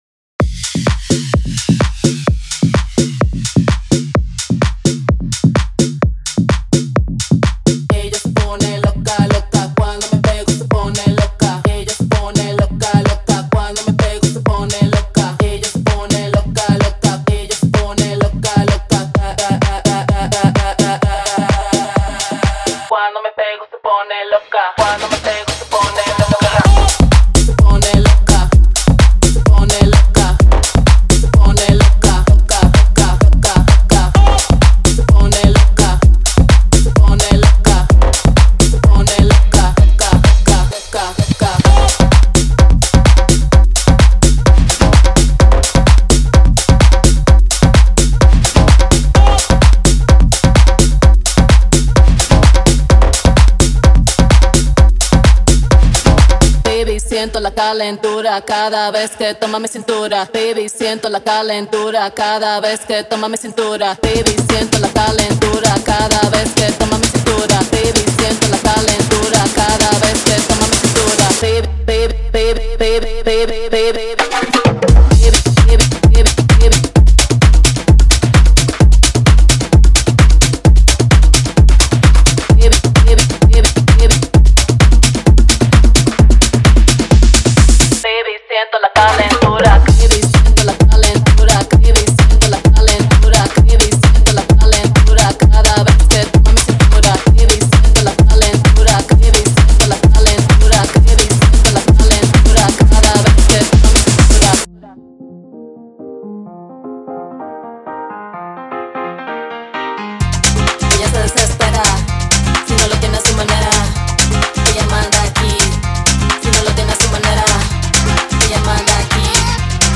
トレンドのラテンテックハウスのサウンドが満載です。
なお、オーディオデモは、音量が大きく、圧縮され、均一な音に処理されています。
Genre:Tech House
53 Vocal Loops
8 Instrument Loops (Piano, Trumpet, Flute)
10 Demo Mixes